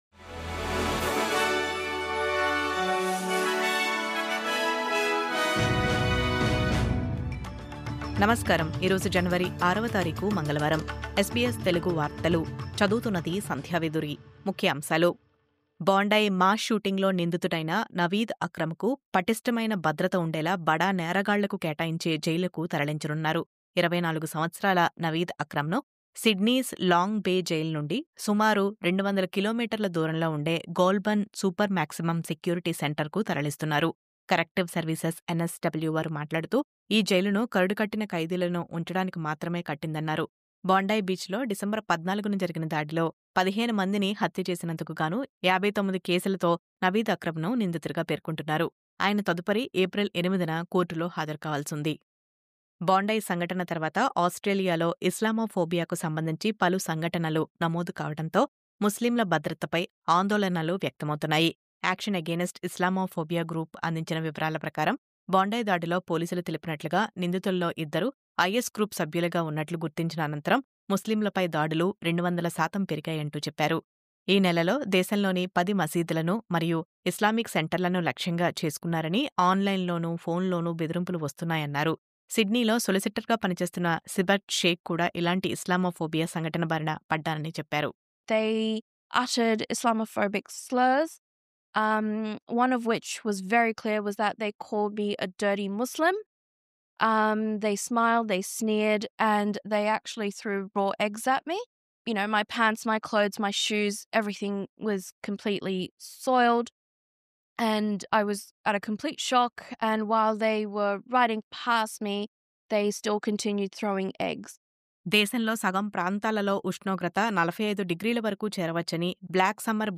News update: 'మిలిటరీ దాడుల ఖర్చు వెనెజుయెలా చమురుతోనే' – ట్రంప్